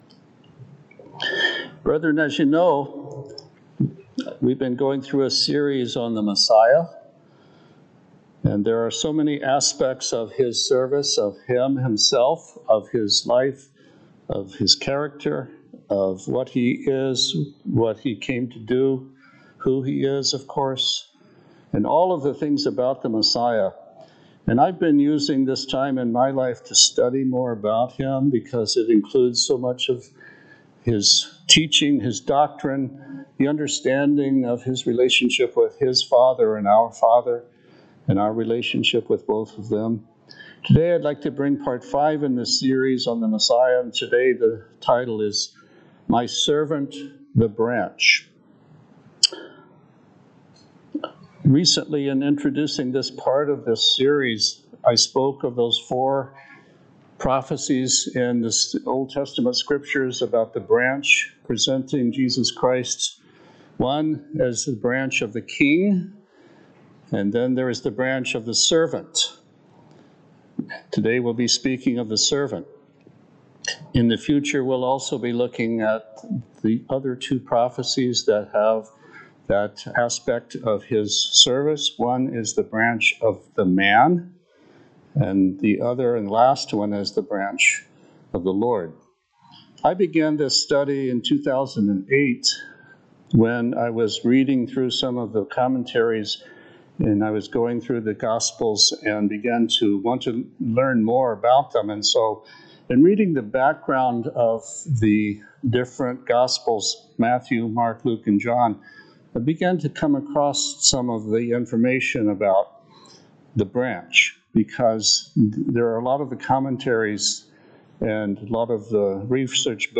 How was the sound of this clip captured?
Given in Olympia, WA Tacoma, WA